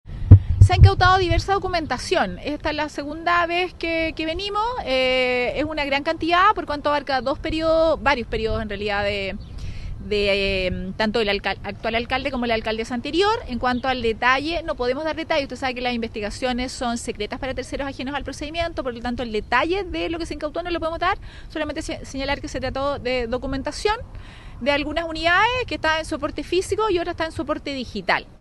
La fiscal María Consuelo Oliva detalló que en el allanamiento se incautó documentación respaldada tanto física como digitalmente, provenientes de algunas unidades municipales.